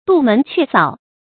杜門卻掃 注音： ㄉㄨˋ ㄇㄣˊ ㄑㄩㄝˋ ㄙㄠˇ 讀音讀法： 意思解釋： 杜：關上。